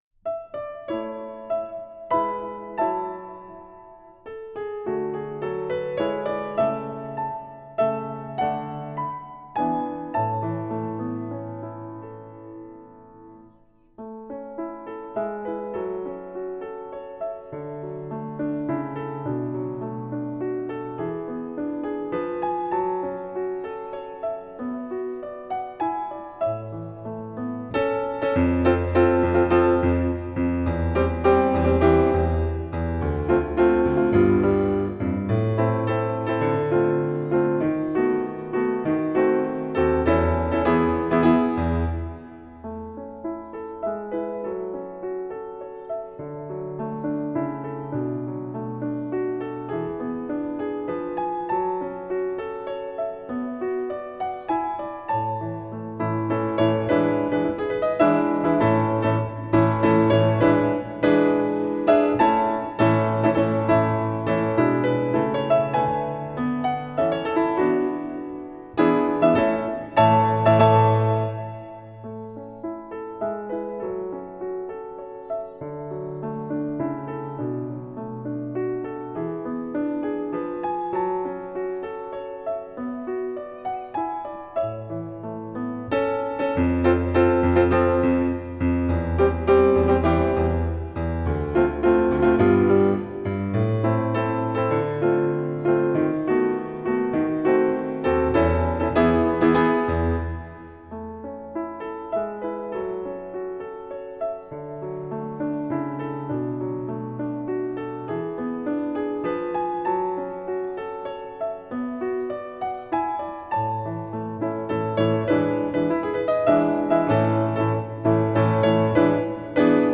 イ長調）ピアノ伴奏（早め）